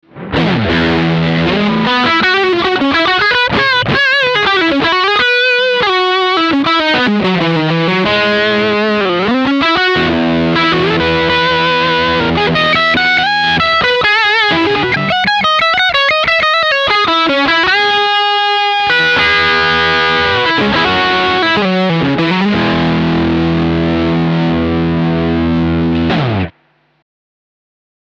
Es legt sich lediglich eine weiche Verzerrung über den Gitarrensound.
Nobels ODR-1 BC Soundbeispiele
Das Nobels ODR-1 BC bietet einen tollen, cremigen Overdrive Sound, der die Grundcharakteristik des Verstärkers kaum färbt.